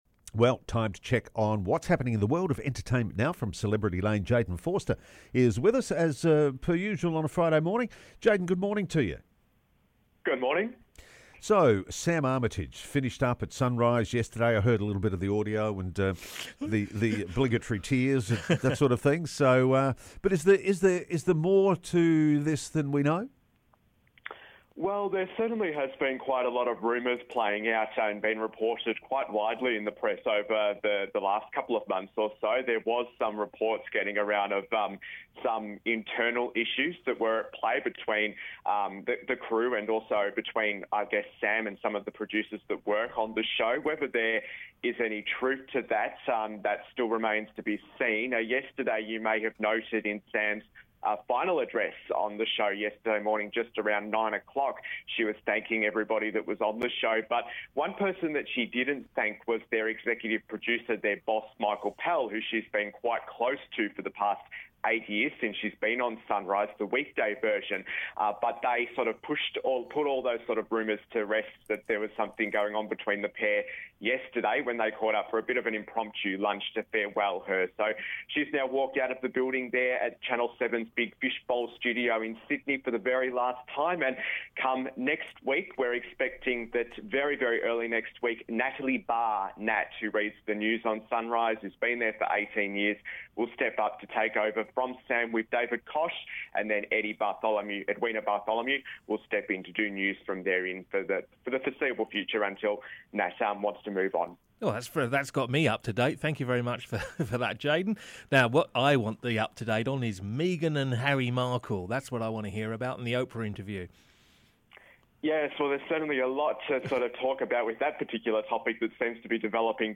Entertainment Reporter